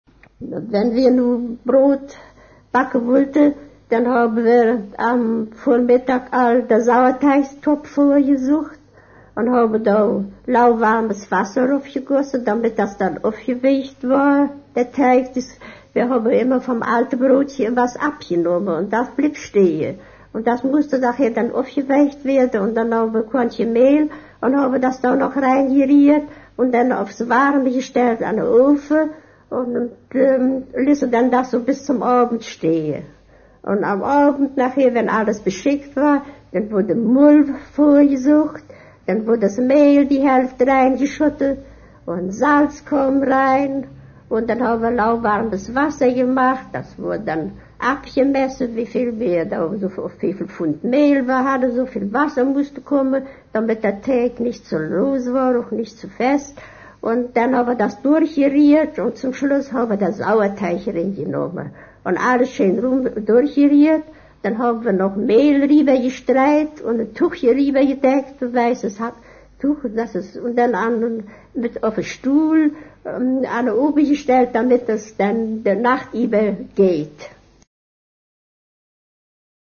Varietäten des Deutschen
Beispiele aus 7 Jahrzehnten und allen deutschen Sprachgebieten
17. Hochpreußisch:
Allenstein Krs. Brückendorf (DSA) 1' 19"
17-Hochpreussisch.mp3